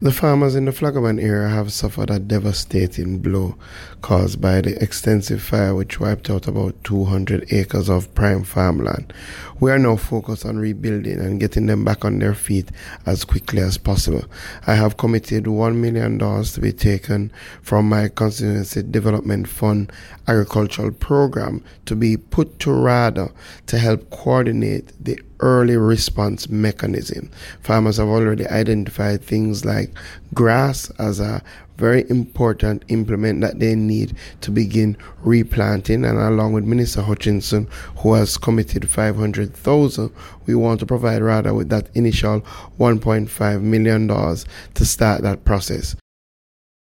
In his address to the farmers on Tuesday, MP Green said there is a plan underway to supply water to the Flagaman area.
MP Green speaks on funds for Flagaman and start-up efforts -